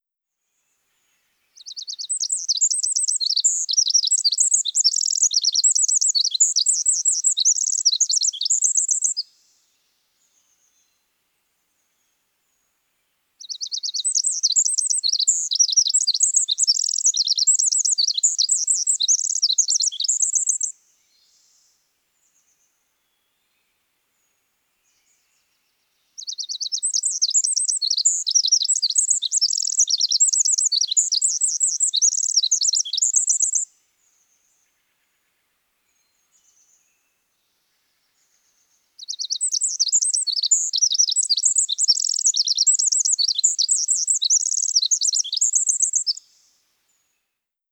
Birds and River
Bird1.wav